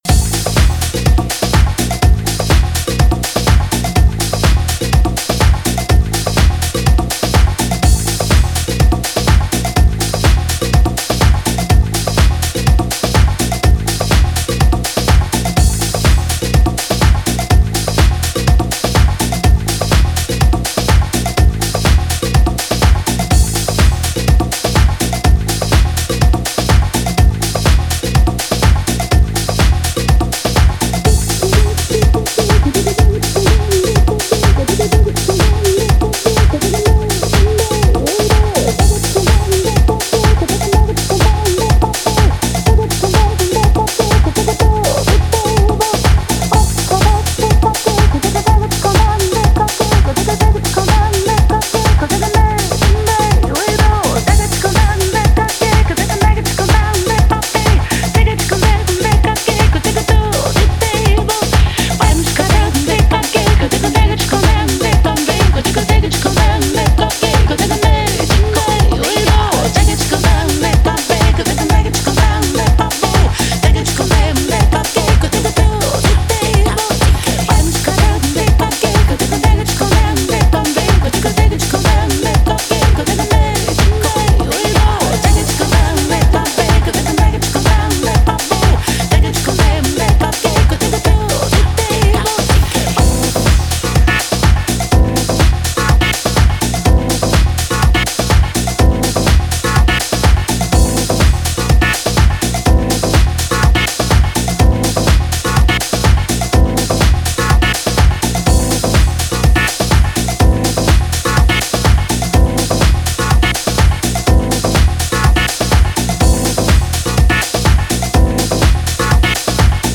专辑类型：Lounge